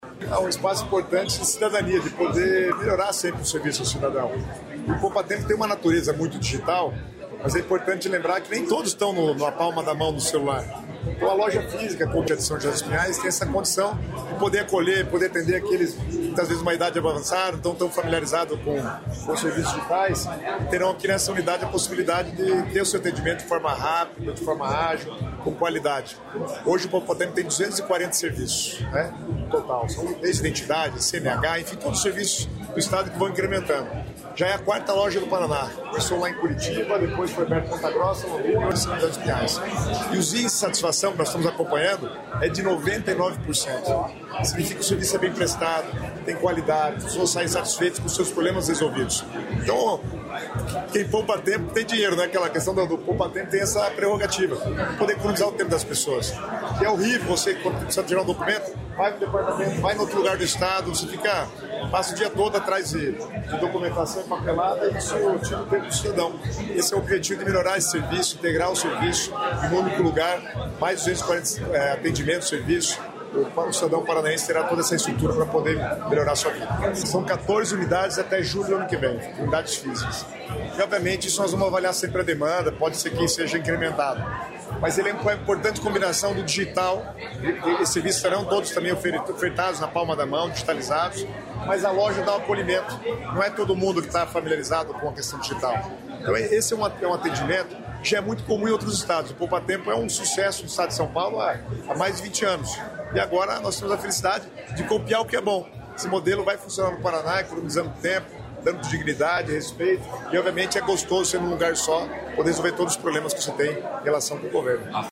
Sonora do secretário das Cidades, Guto Silva, sobre o Poupatempo Paraná inaugurado em São José dos Pinhais